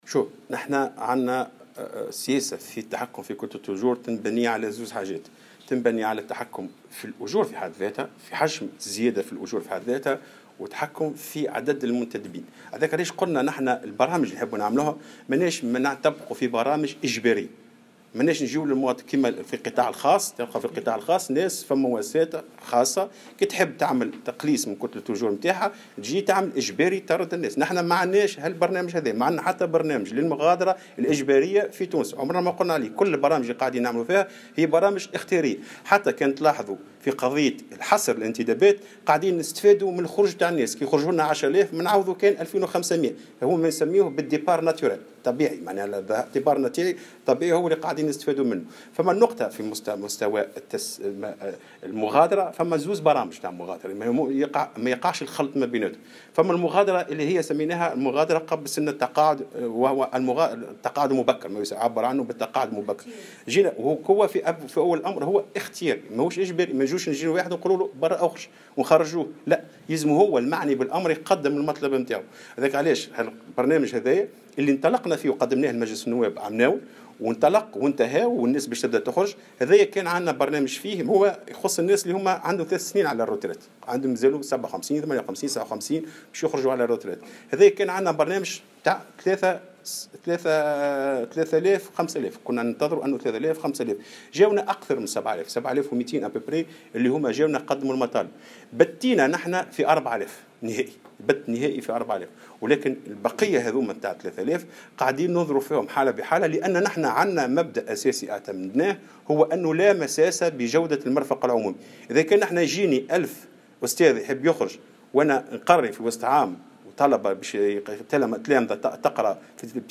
وأوضح لمراسل "الجوهرة اف أم" ان هذه المغادرة تأتي في اطار التقاعد المبكر ومن منطلق مغادرة اختيارية وغير اجبارية.